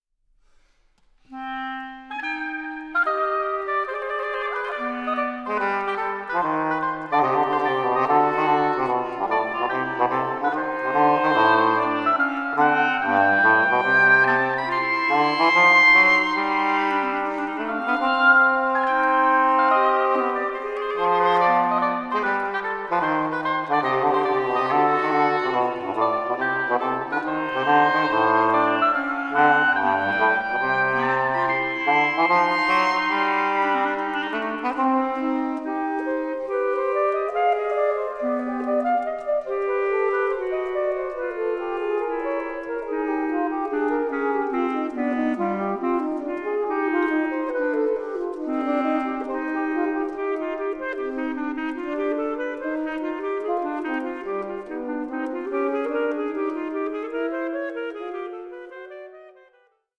Dutch reed quintet
lupophone and english horn